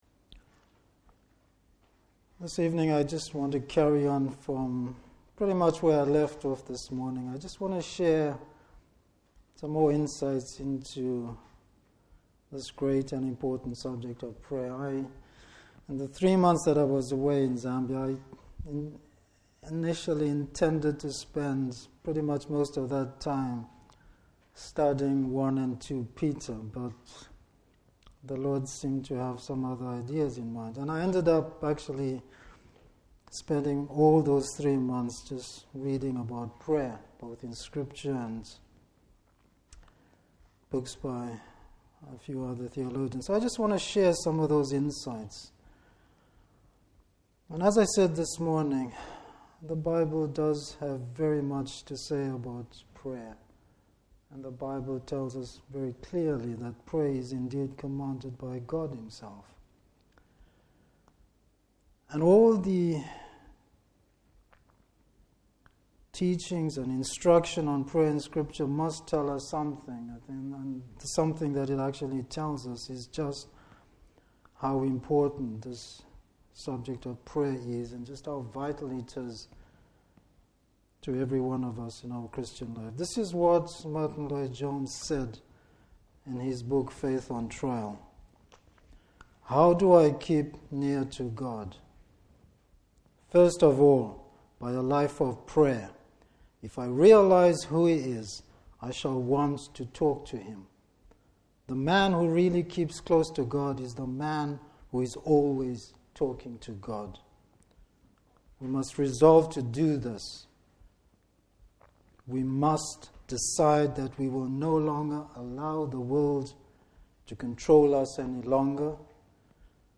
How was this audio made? Service Type: Evening Service Bible Text: Ephesians 1:15-23.